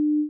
Free UI/UX sound effect: Error Beep.
Error Beep
Error Beep is a free ui/ux sound effect available for download in MP3 format.
027_error_beep.mp3